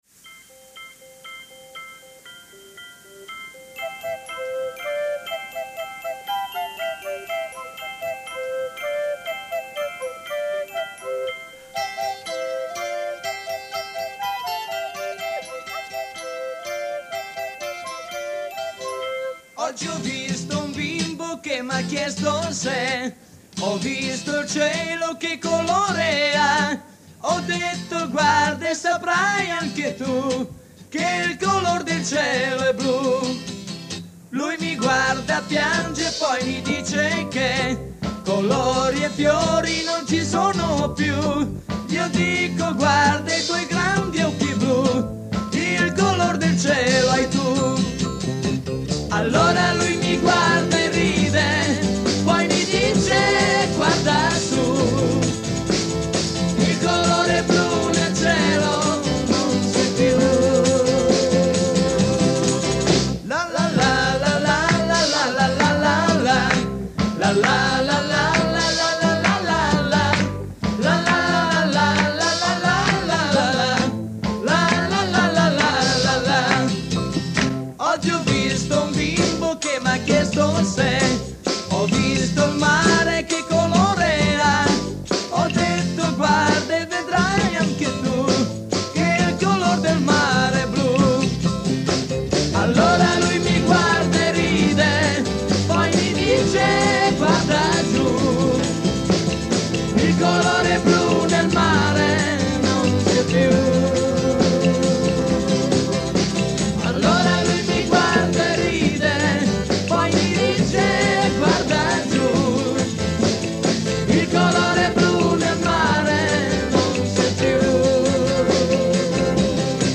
(Provini realizzati in sala prove)